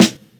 SGD_SNR.wav